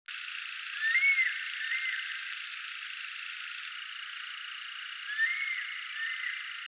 39-大冠鷲1.mp3
物種名稱 大冠鷲 Spilornis cheela hoya
錄音地點 高雄市 六龜區 扇平
NA 錄音環境 森林 發聲個體 行為描述 鳥叫 錄音器材 錄音: 廠牌 Denon Portable IC Recorder 型號 DN-F20R 收音: 廠牌 Sennheiser 型號 ME 67 標籤/關鍵字 備註說明 MP3檔案 39-大冠鷲1.mp3